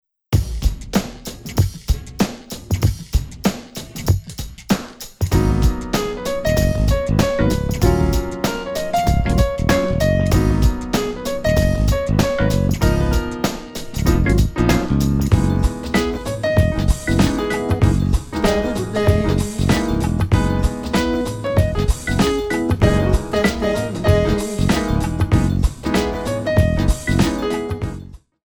8 bar intro
up-tempo
Hip-Hop / Funk / Jazz